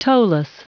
Prononciation du mot toeless en anglais (fichier audio)
Prononciation du mot : toeless